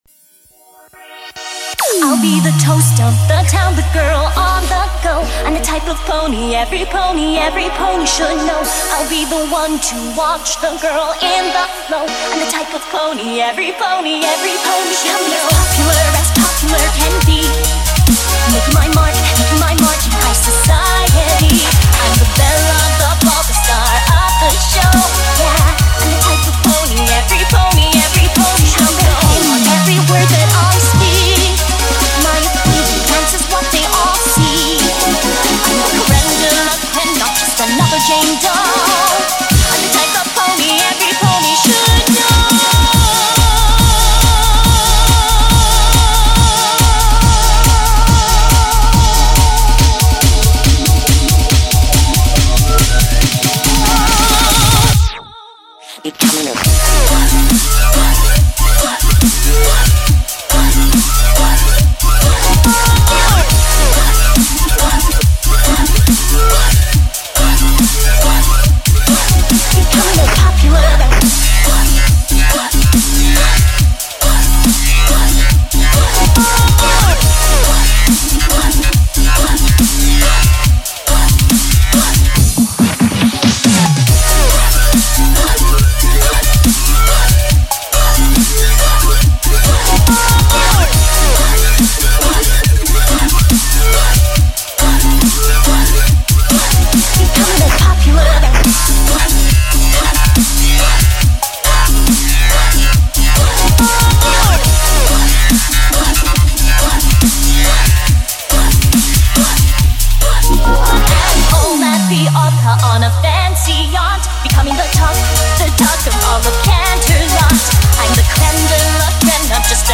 Completely changed the mood of the track, hope you enjoy :D